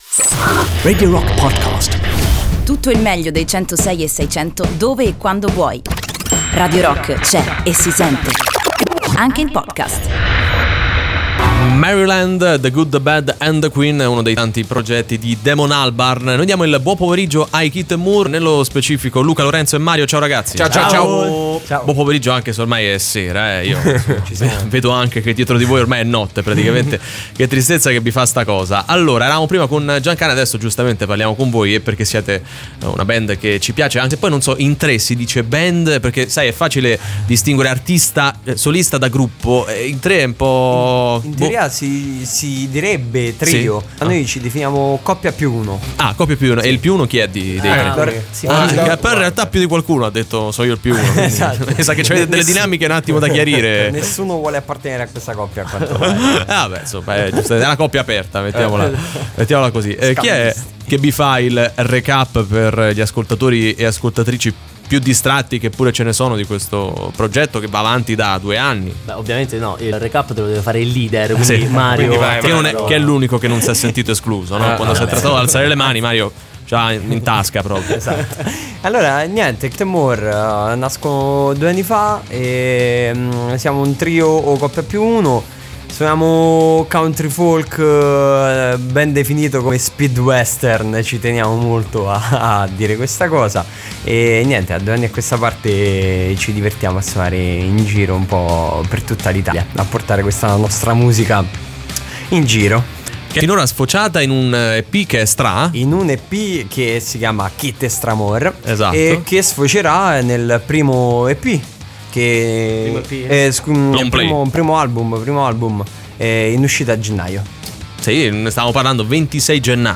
Intervista: Keet & More (02-11-18)